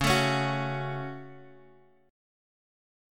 Dbsus4#5 chord